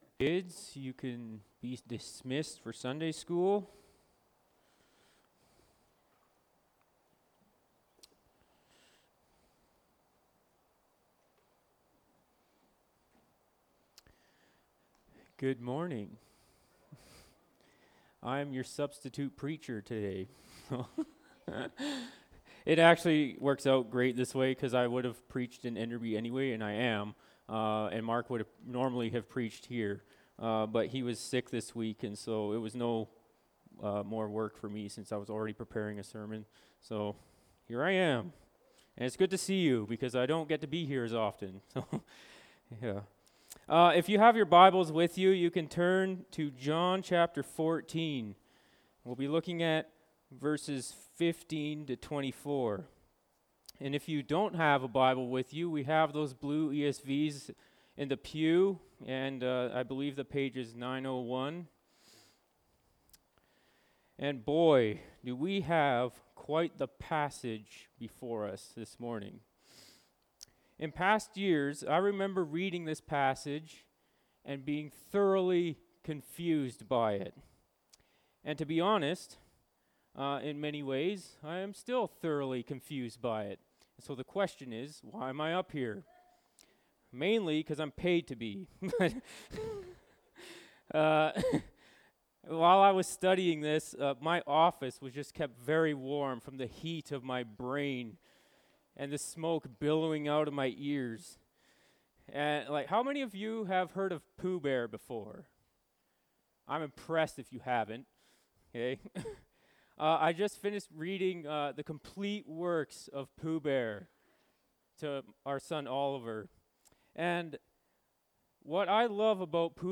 Feb 11, 2024 Loving Jesus (John 14:15-24) MP3 SUBSCRIBE on iTunes(Podcast) Notes Discussion Sermons in this Series This sermon was recorded at Grace Church - Salmon Arm and preached in both Salmon Arm and Enderby.